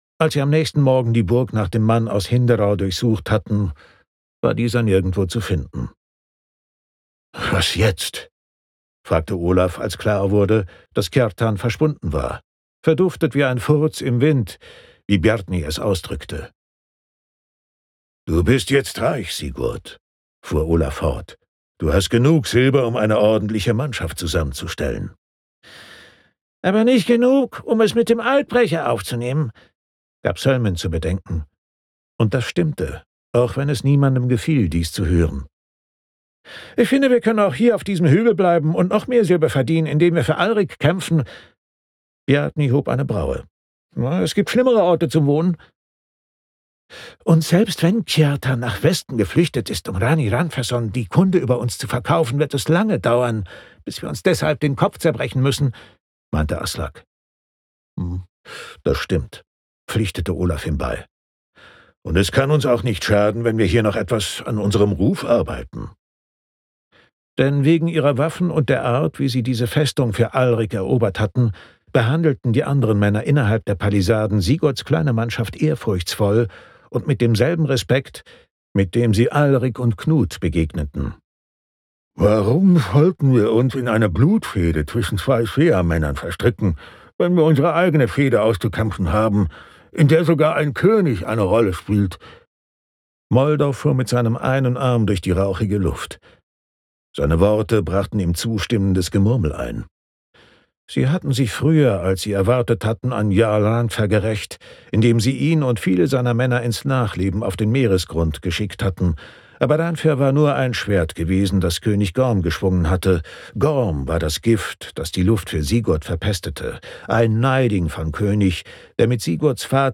Themenwelt Literatur Historische Romane